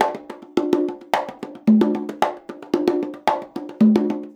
110 CONGA 8.wav